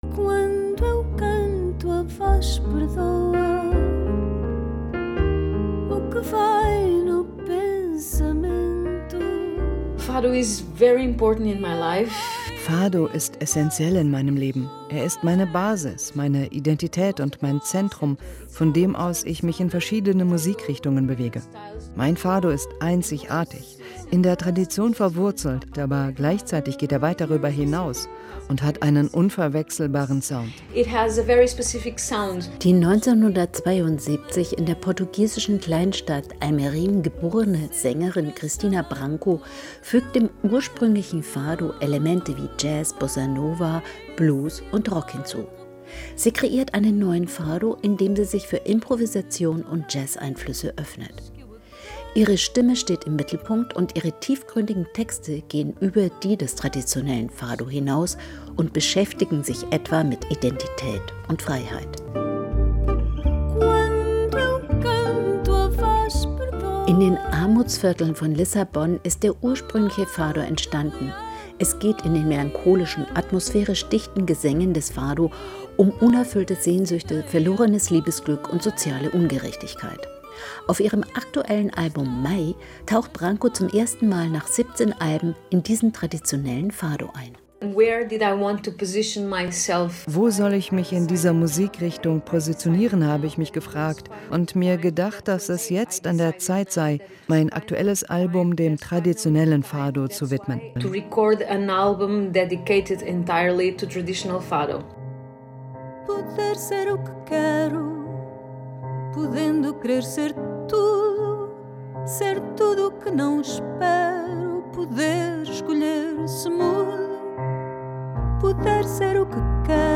Porträt
Ihre Musik hat eine gewisse Leichtigkeit und Verspieltheit und entgeht der „Saudade“, der Sehnsucht, die das Genre prägt.
Die 1972 in der portugiesischen Kleinstadt Almeirim geborene Sängerin Cristina Branco fügt dem ursprünglichen Fado Elemente wie Jazz, Bossa Nova, Blues, Jazz und Rock hinzu.